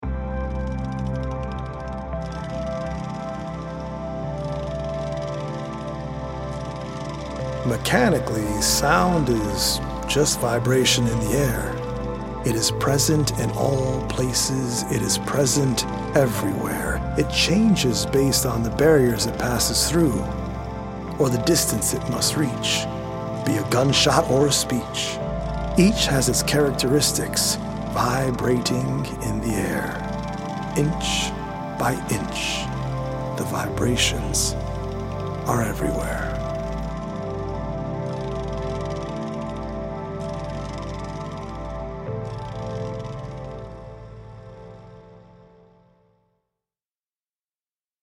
100 original poems written/performed
healing Solfeggio frequency music
EDM